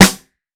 Aftermath Snare.wav